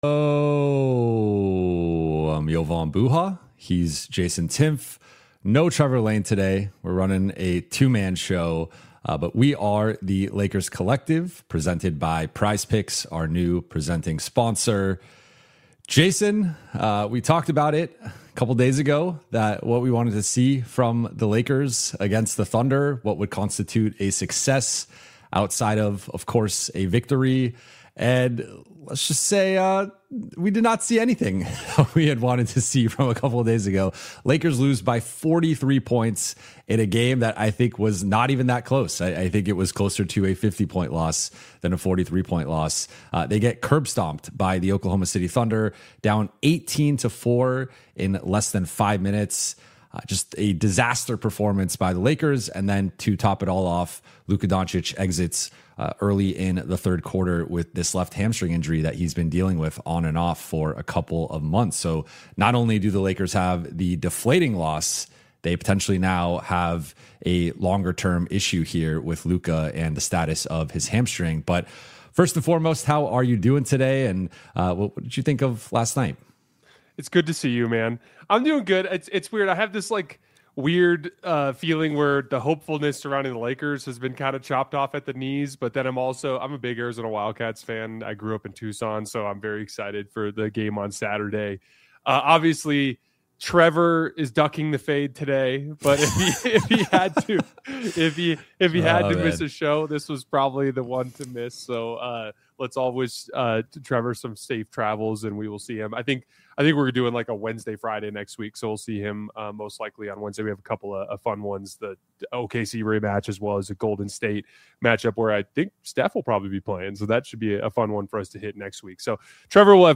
a weekly Los Angeles Lakers roundtable